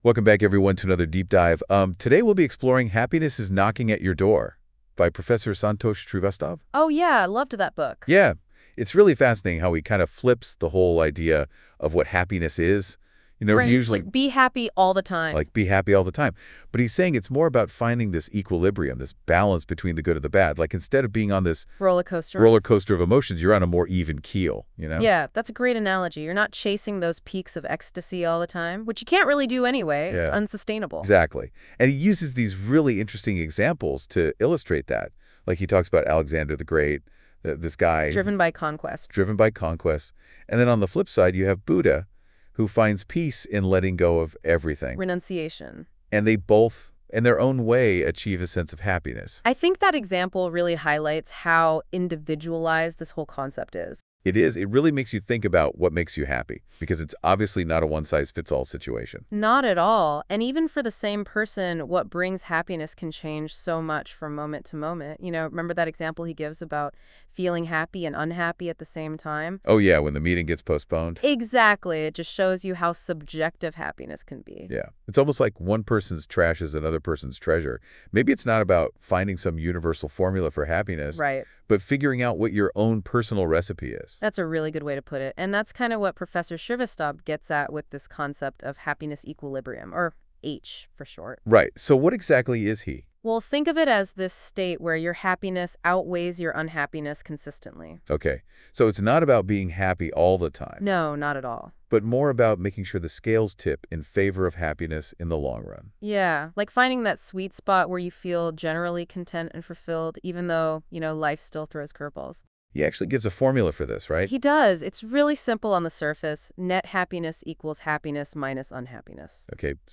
Real Meaning of Happiness - The Happiness Equilibrium By Dr. S. K. Shrivastav The Happiness Equilibrium NotebookLM Introductory Summary We all desire happiness, but do we truly understand what it means?